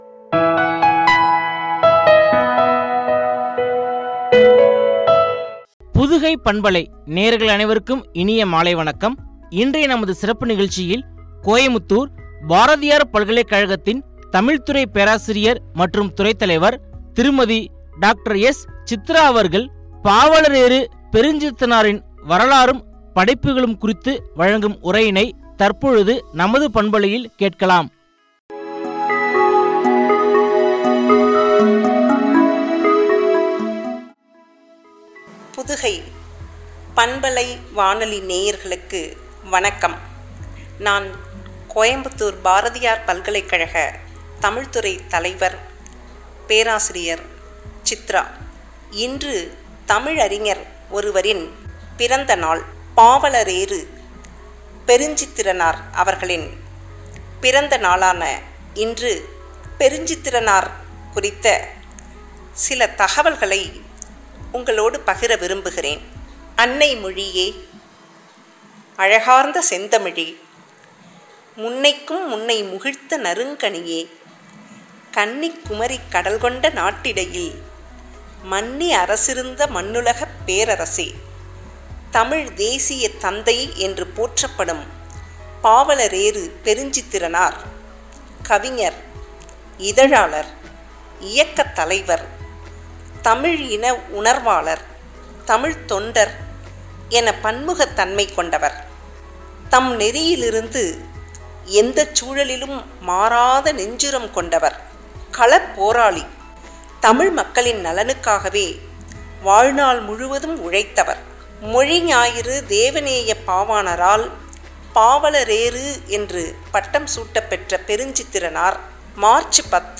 படைப்புகளும்” குறித்து வழங்கிய உரையாடல்.